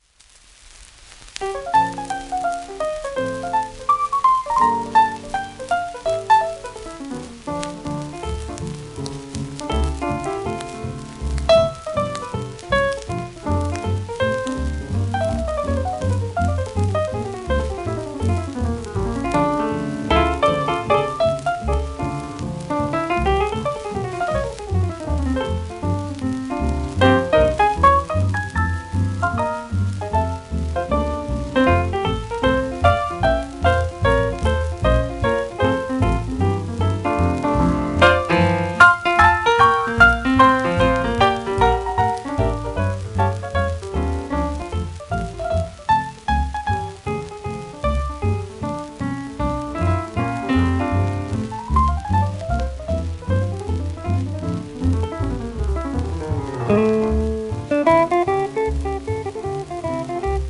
1946年頃録音